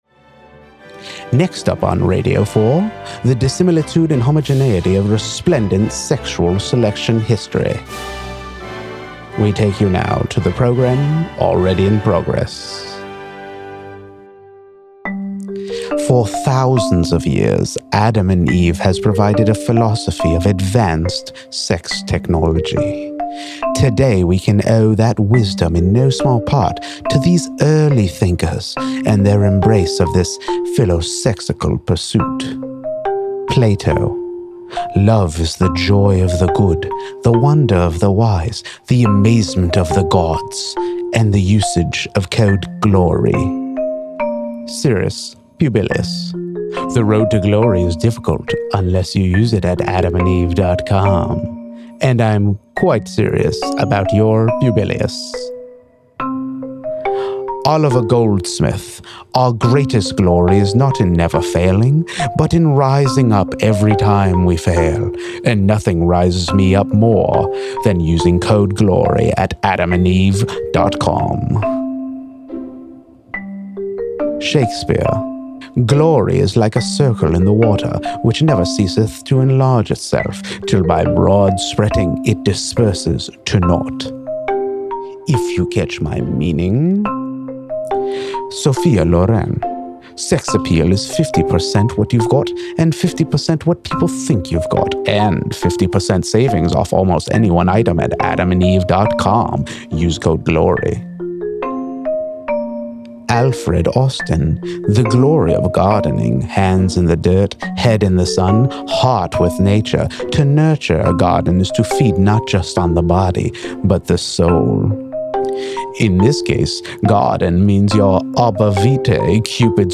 I'm going to have that xylophone tune stuck in my head all day.